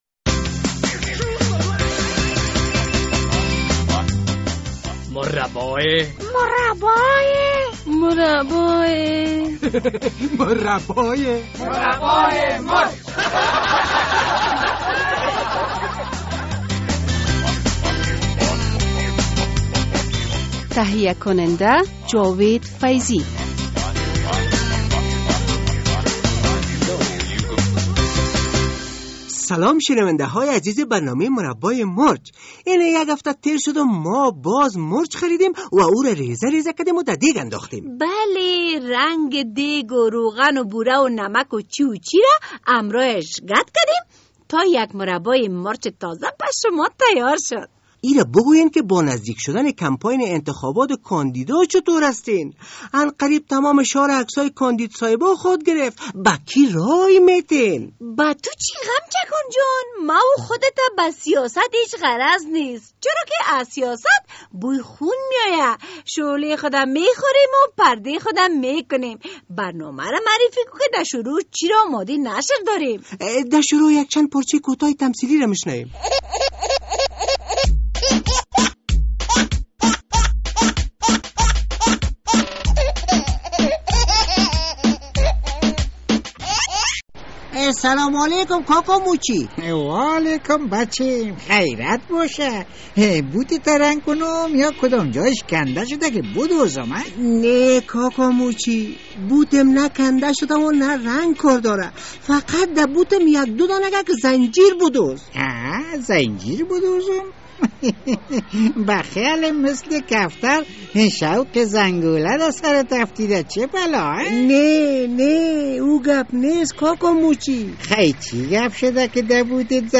مصاحبه با یک حیوان قوی هیکل